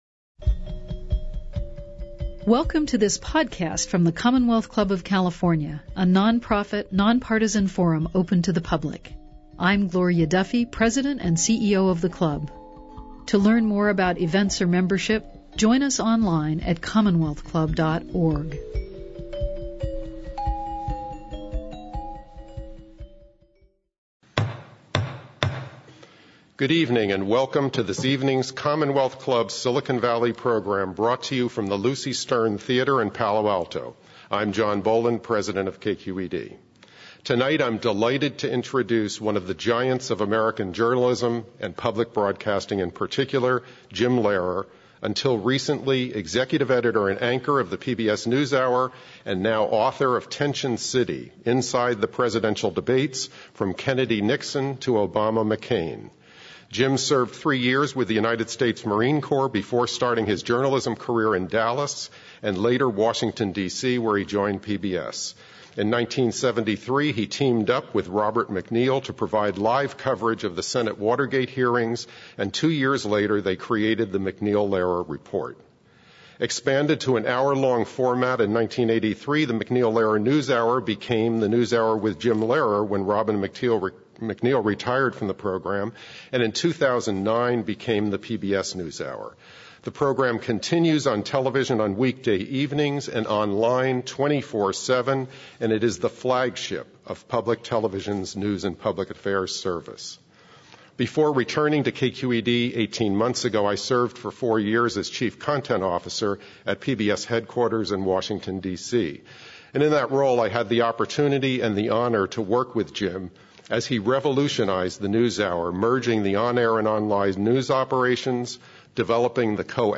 In conversation with David Kennedy, Donald J. McLachlan Professor of History Emeritus, Stanford University As the “man in the middle seat,” legendary broadcaster Lehrer has presided more than 11 presidential and vice-presidential debates.